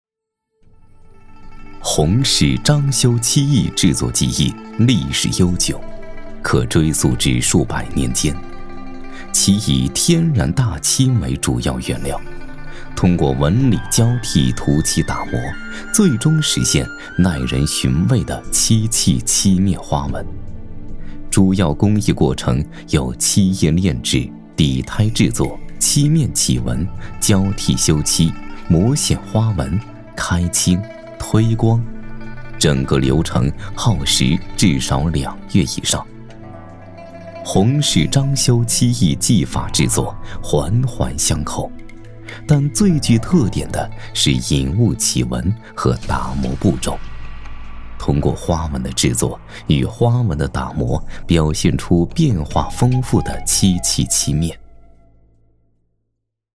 男国语114